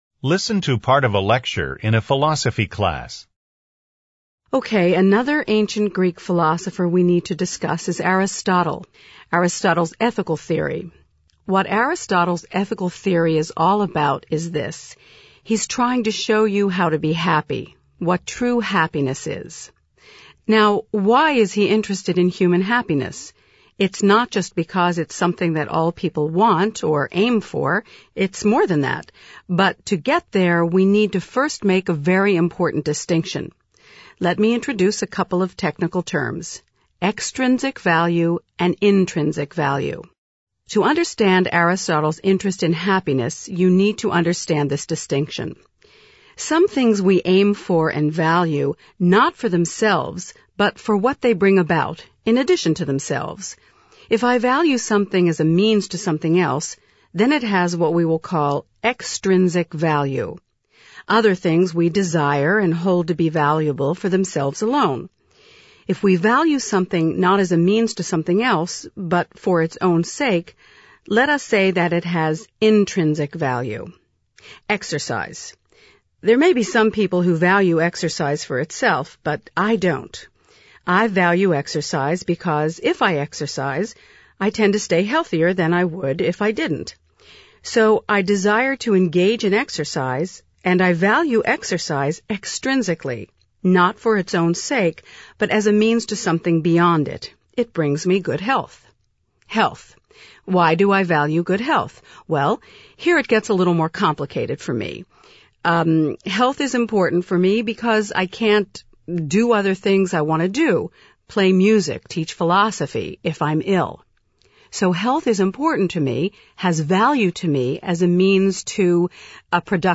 • 講義形式　Lecture
登場人物は「教授のみ」、または「教授と数名の生徒」。心理学、哲学、環境科学、地質学、考古学、天文学、歴史など、様々なアカデミック分野を題材とした講義内容。